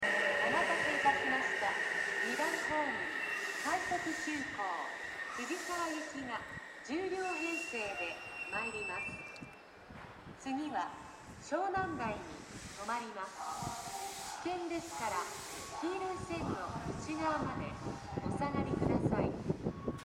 この駅では接近放送が設置されています。
２番ホームOE：小田急江ノ島線
接近放送快速急行　藤沢行き接近放送です。